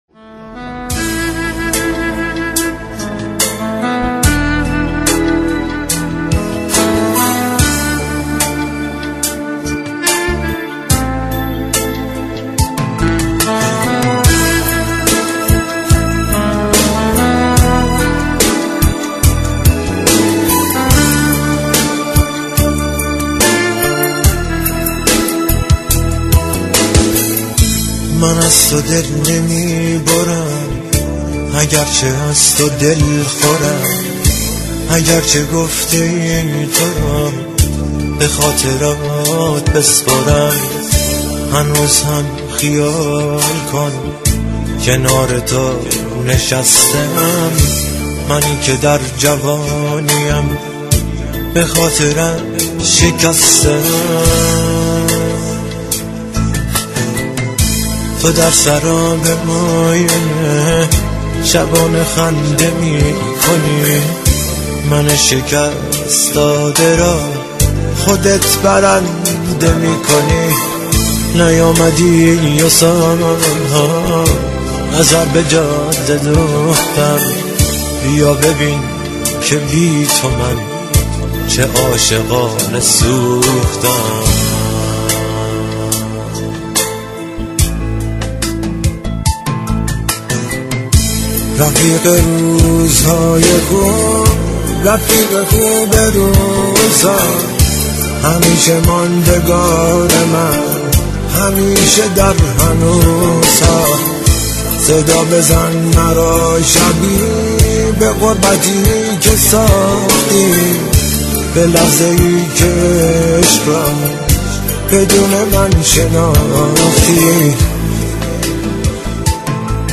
آهنگ غمگین